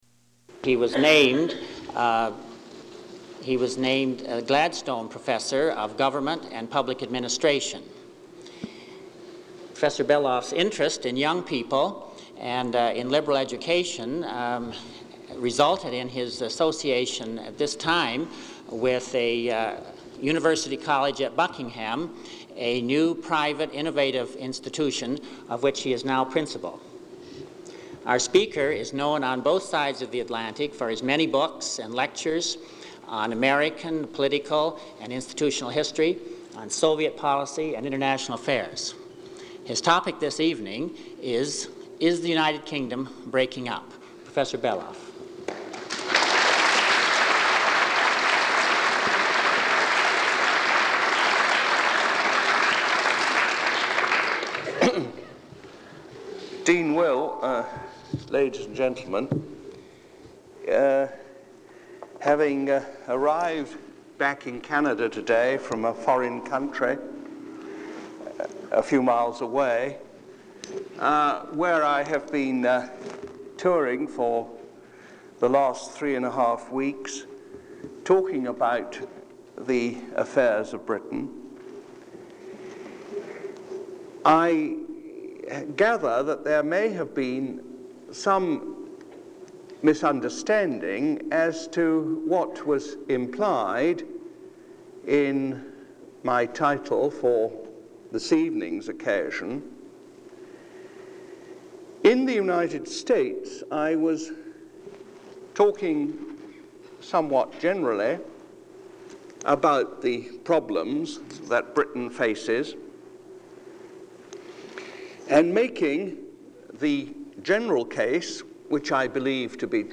Item consists of a digitized copy of an audio recording of a Vancouver Institute lecture given by Max Beloff on November 8, 1975.